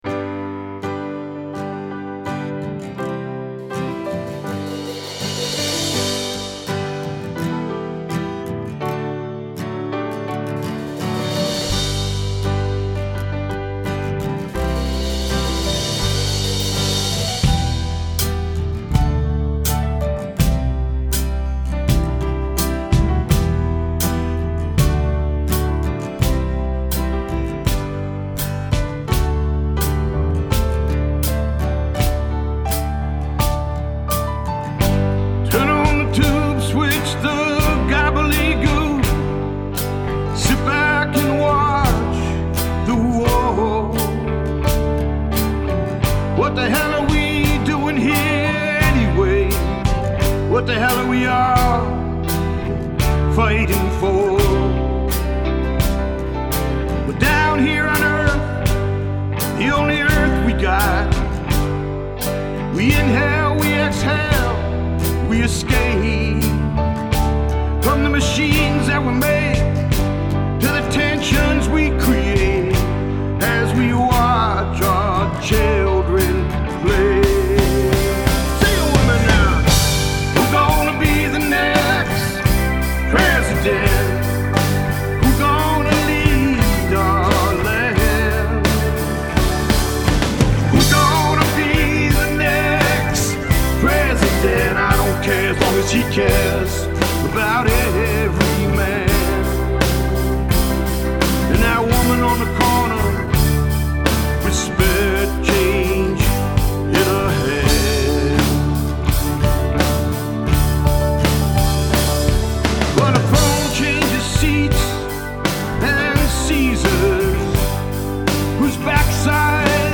I track drums from start to finish without fixes.